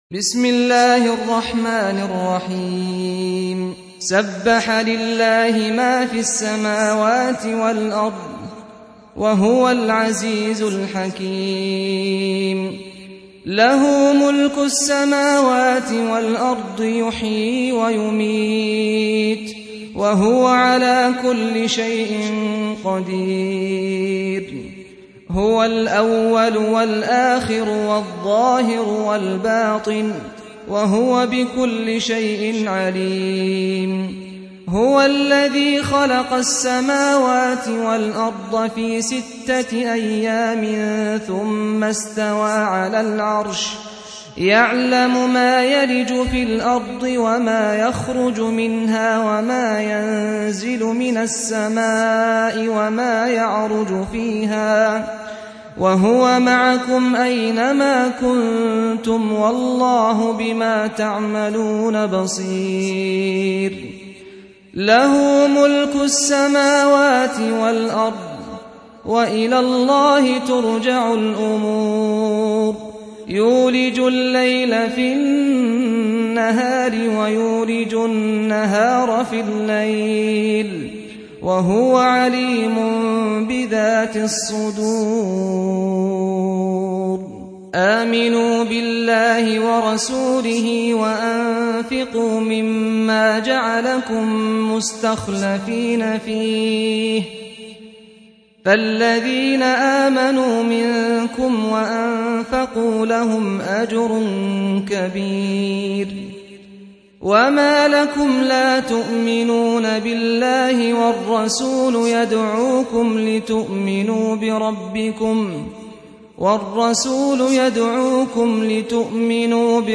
Surah Repeating تكرار السورة Download Surah حمّل السورة Reciting Murattalah Audio for 57.